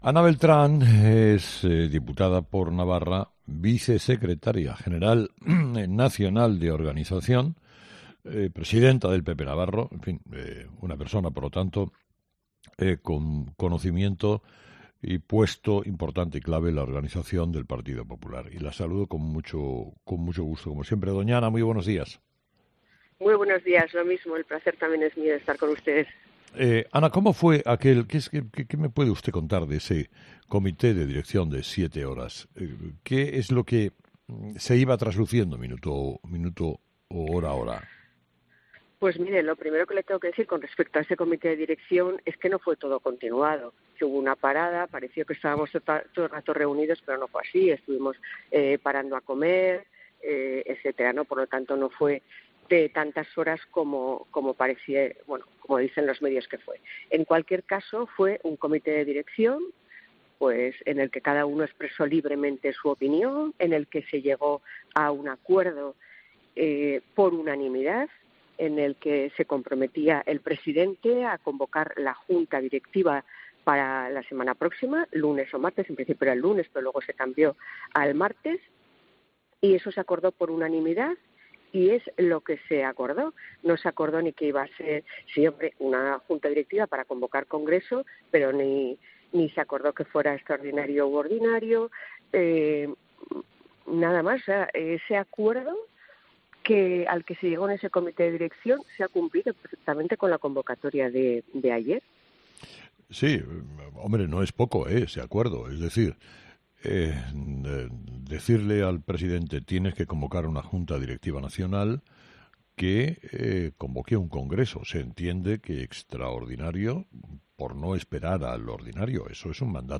La Vicesecretaria de Organización del partido se pronuncia en Herrera en COPE ante la deriva de actos que se suceden dentro del Partido Popular